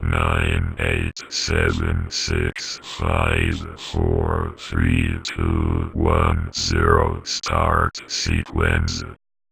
VVE1 Vocoder Phrases 10.wav